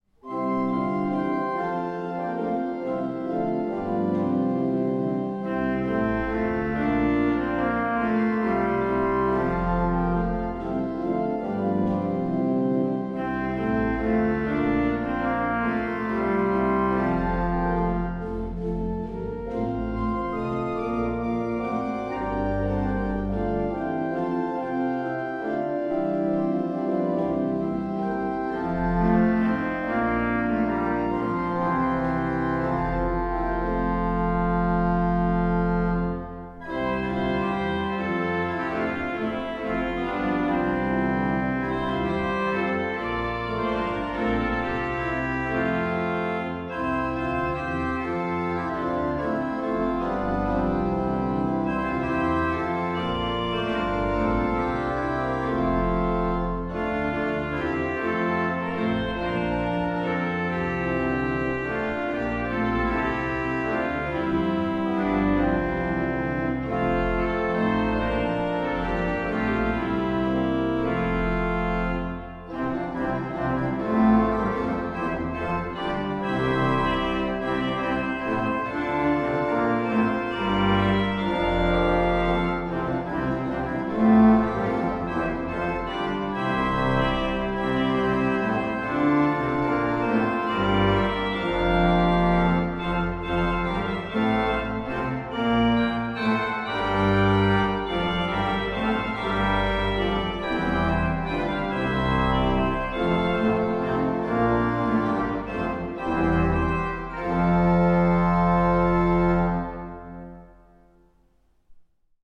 Burgrieden, SPÄTH / REISER-Orgel
Katholische Pfarrkirche St. Alban, 88483 Burgrieden
Vorspiel & zwei Strophen: Helltrompete 8' solistisch in Tenor/Sopran und Basslage